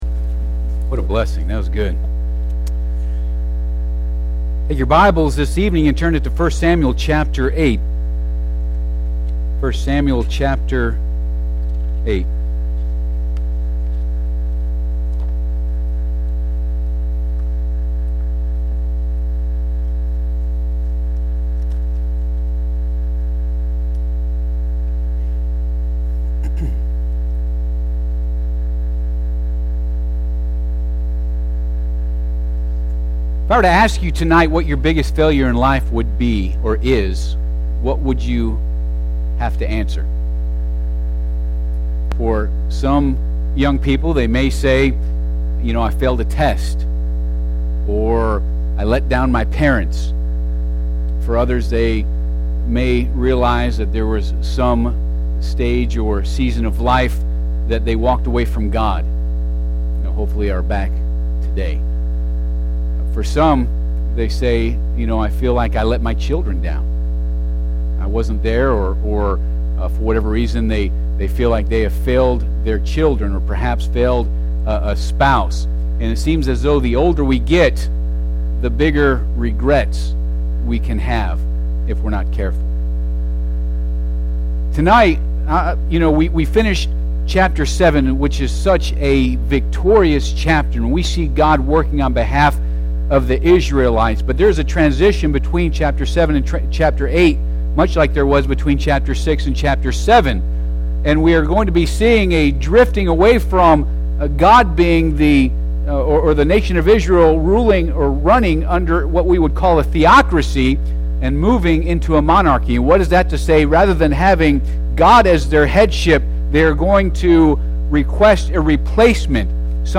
1 Samuel 8 Service Type: Sunday PM Bible Text